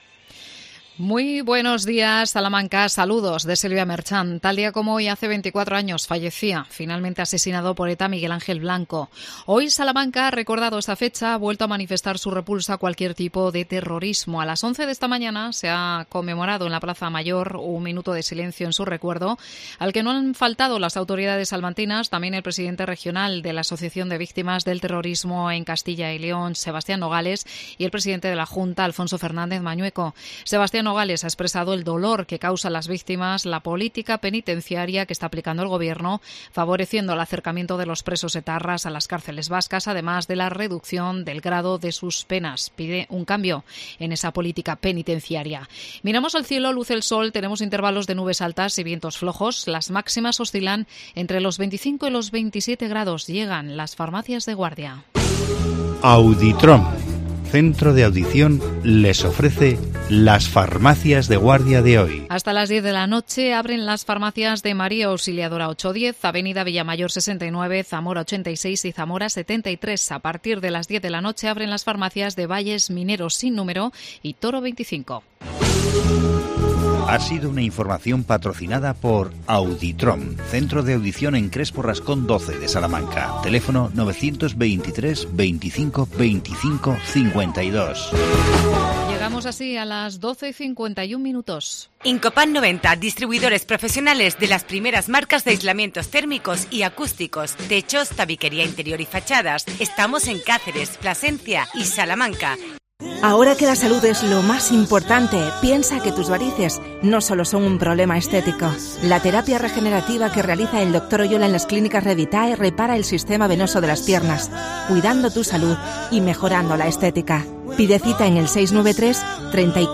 Entrevista a la concejala de Mayores Isabel Macías. Presentación de la nueva plataforma de formación online para los mayores.